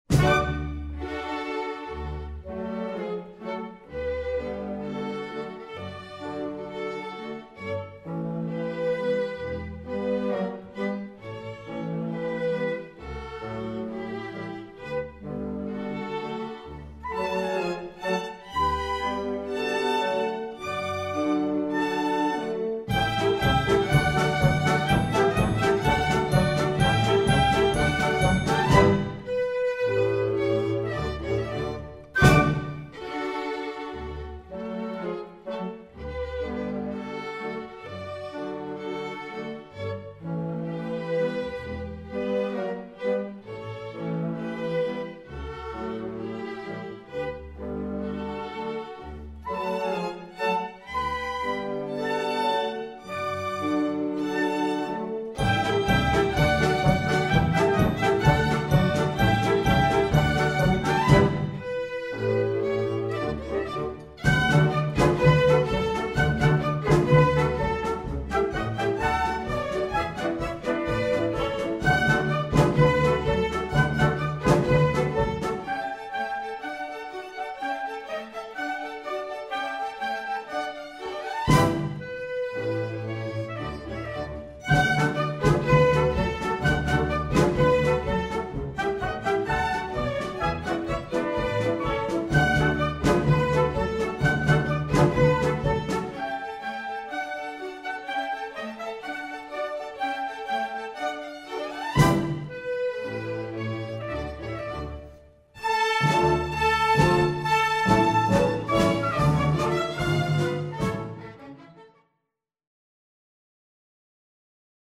Full Orchestra (MD)   Score